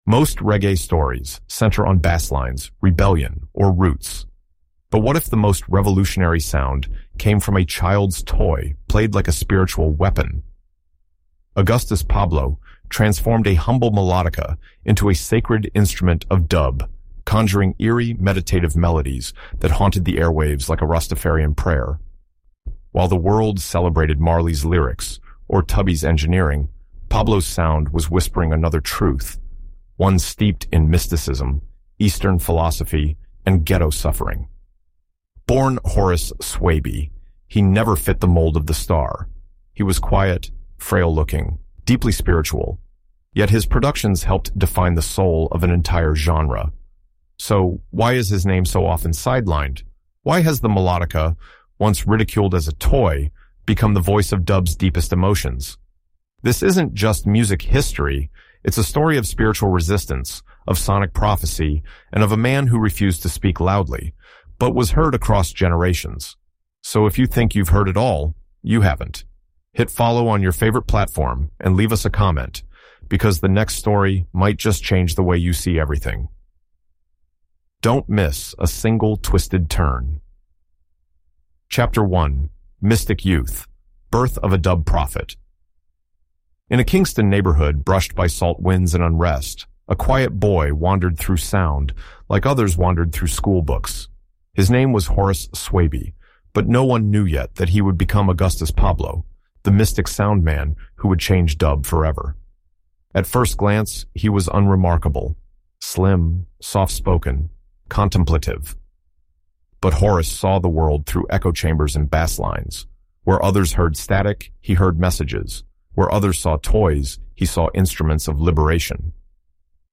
In this episode of our Caribbean podcast, history experts and music storytellers trace Pablo’s journey from Kingston’s backstreets to global cult fame.